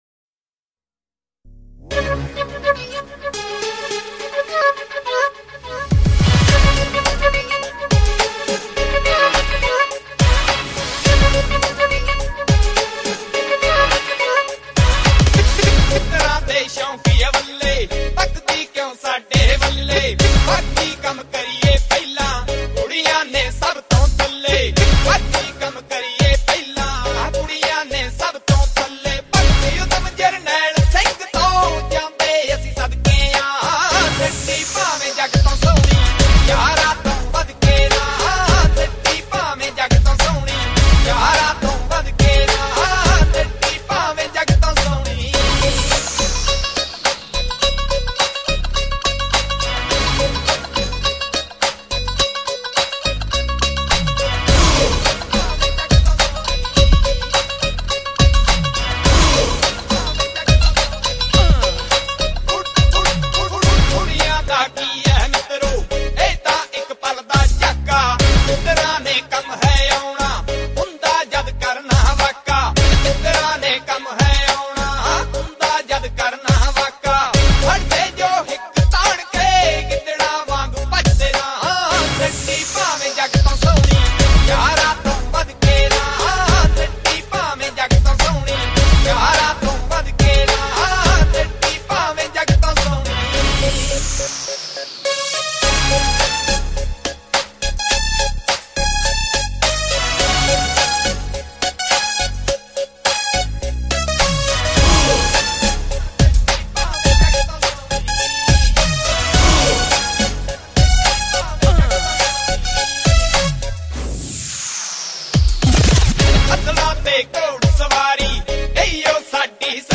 Genre Punjabi Old Song